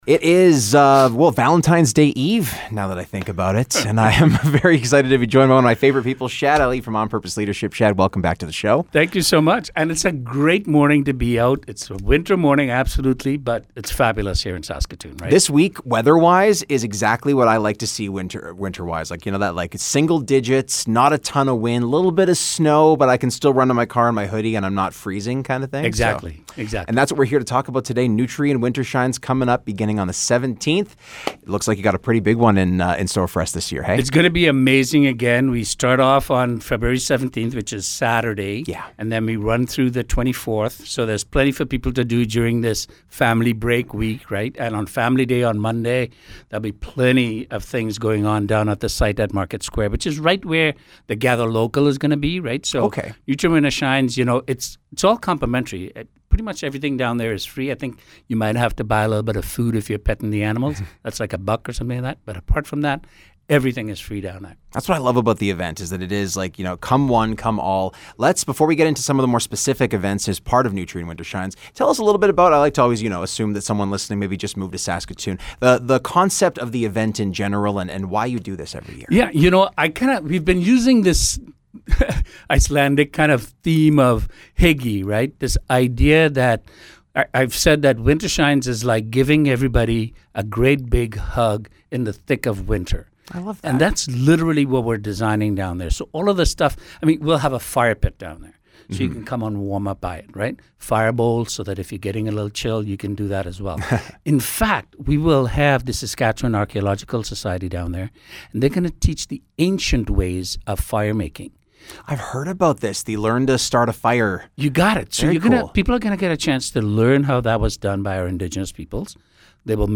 Interview: Nutrien Wintershines 2024